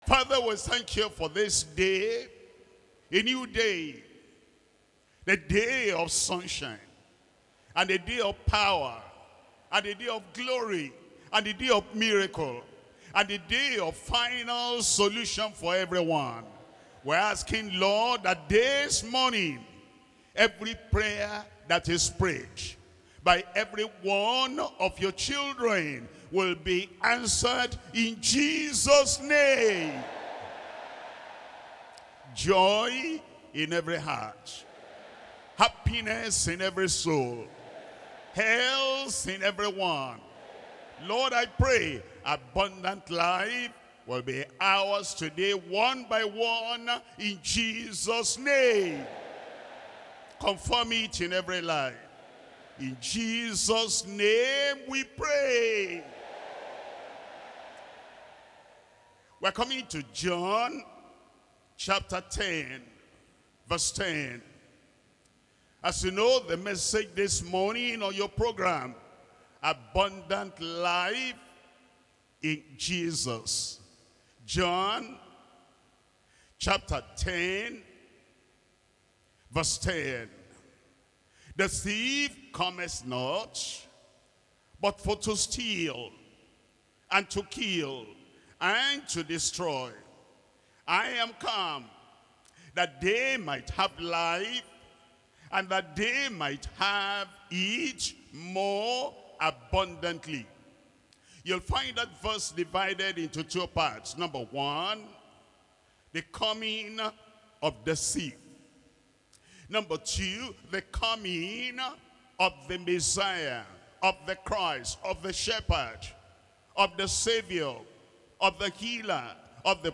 Sermons - Deeper Christian Life Ministry
2025 Global December Retreat